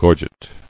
[gor·get]